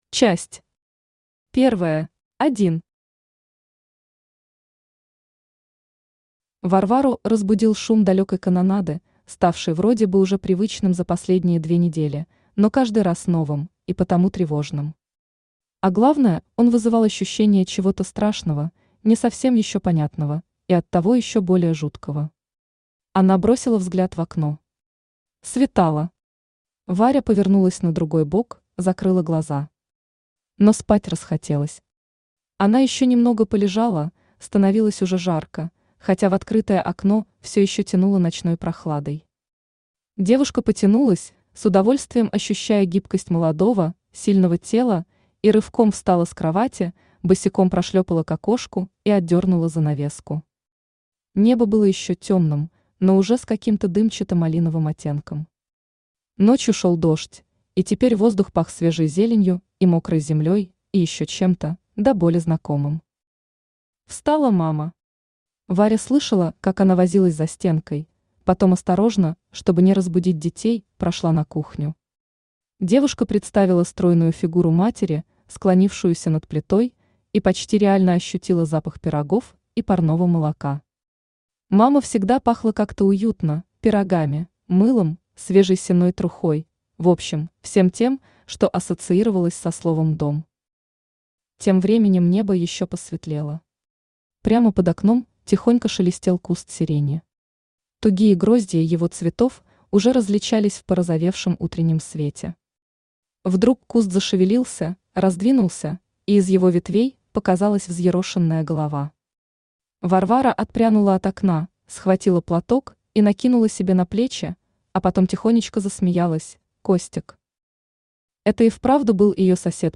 Аудиокнига Счастье в огне | Библиотека аудиокниг
Aудиокнига Счастье в огне Автор Мария Захарова Читает аудиокнигу Авточтец ЛитРес.